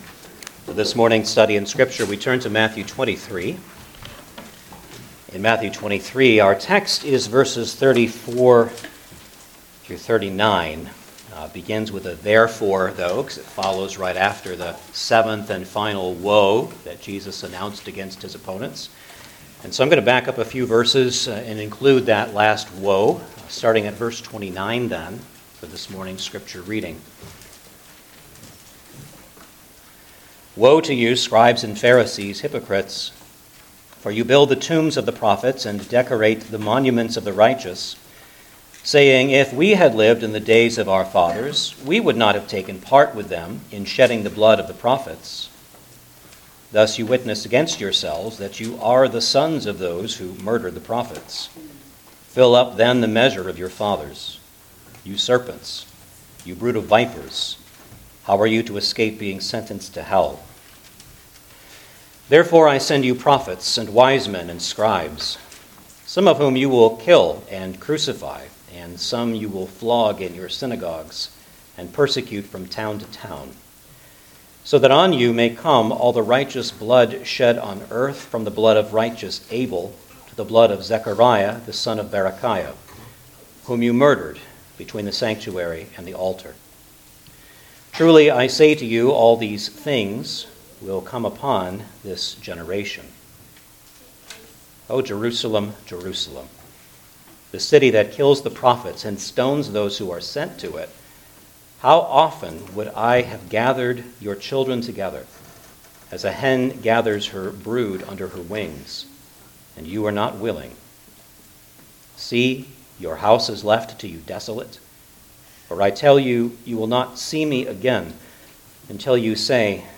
Gospel of Matthew Passage: Matthew 23:34-39 Service Type: Sunday Morning Service Download the order of worship here .